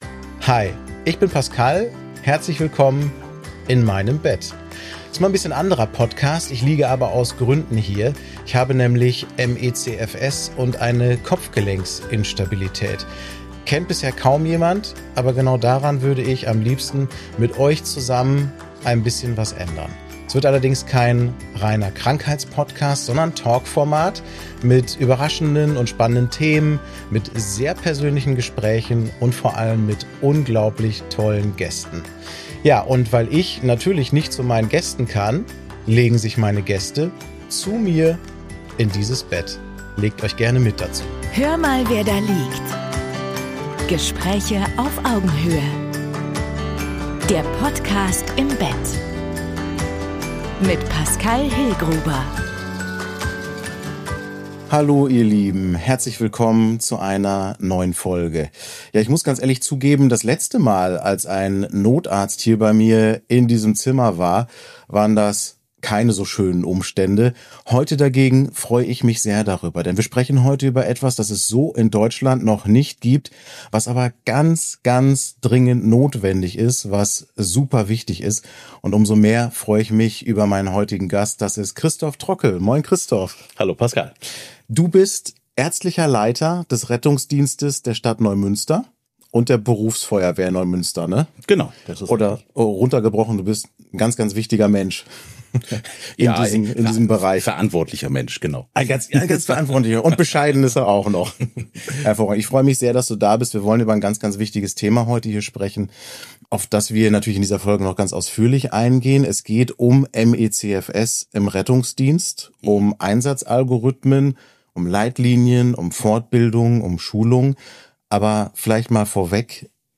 Ein Gespräch über Verantwortung, Haltung und Hoffnung.